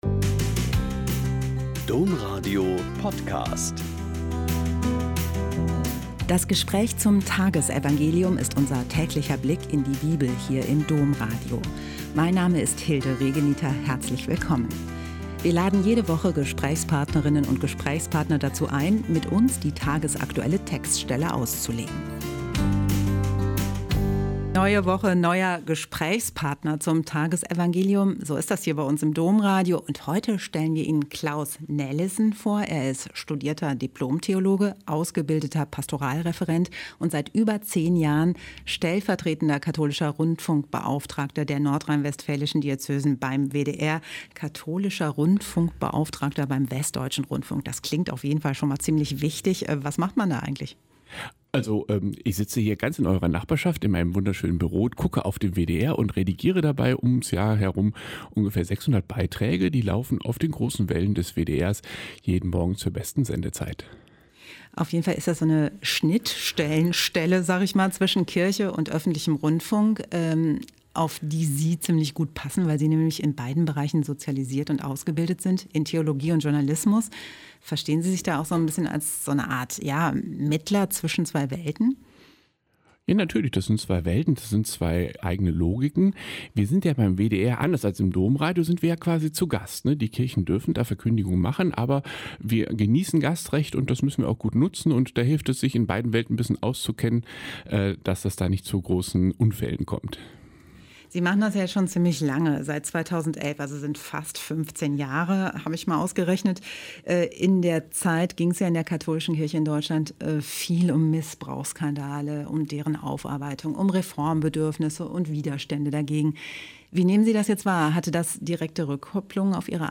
Lk 8,16-18 - Gespräch